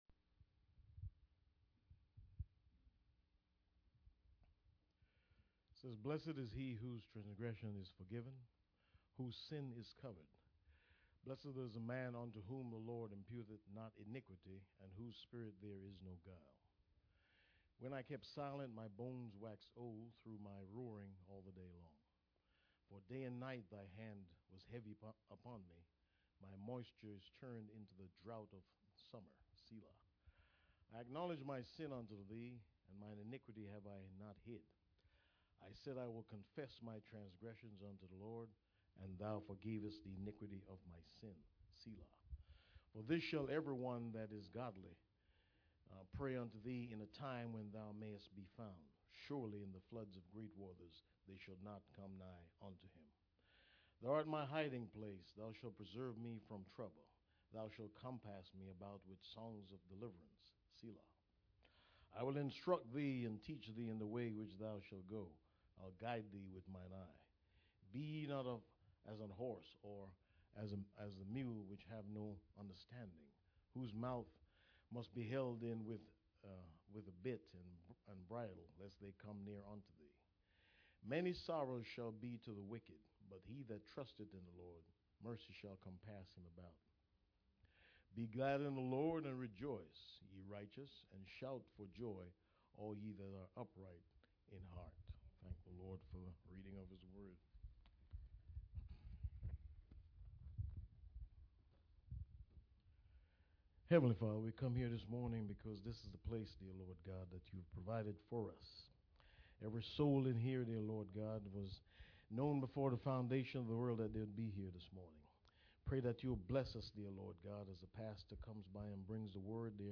Ephesians 2:1 Service Type: Sunday Morning %todo_render% « Revelation Chapter 13